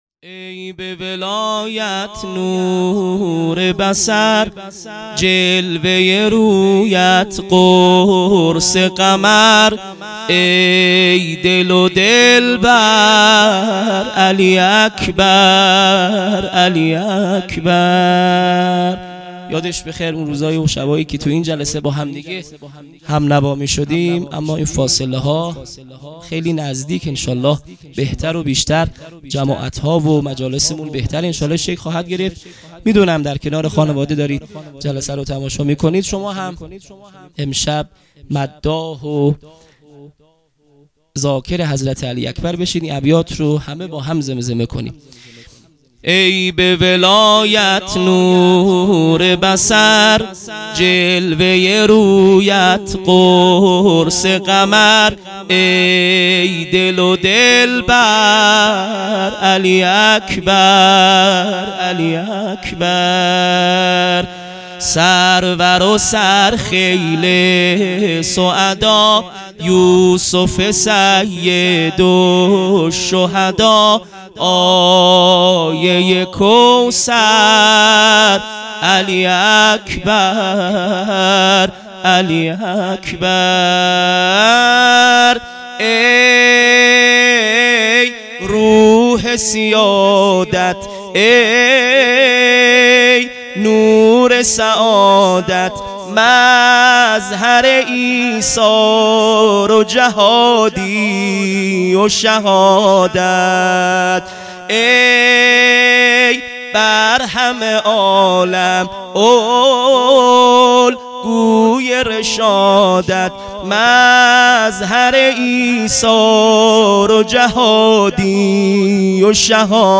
هیئت مکتب الزهرا(س)دارالعباده یزد - مدح | ای به ولایت نور بصر مداح